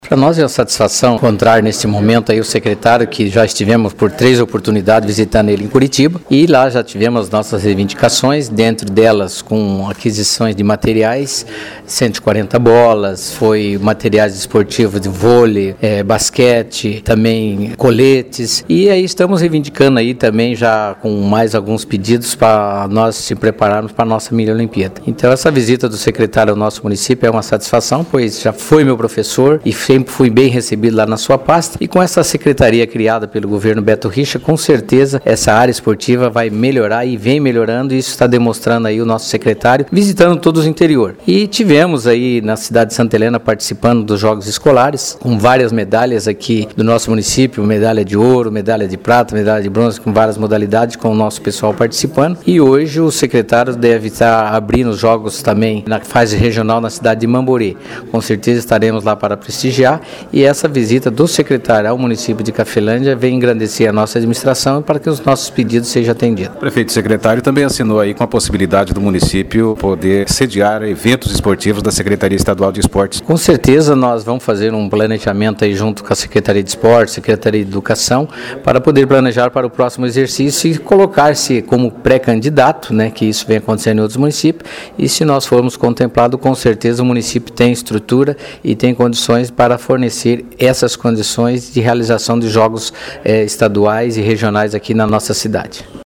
Entrevista com o prefeito Bugrão